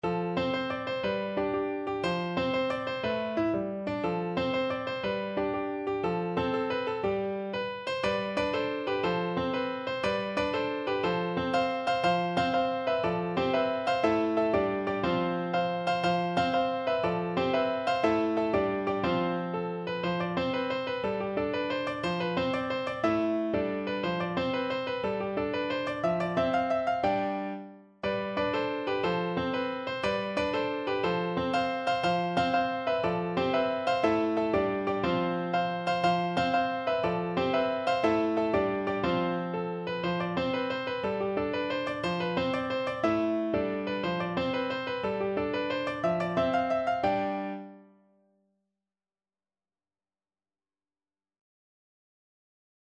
Piano version
No parts available for this pieces as it is for solo piano.
6/8 (View more 6/8 Music)
With energy .=c.120
Piano  (View more Intermediate Piano Music)
Classical (View more Classical Piano Music)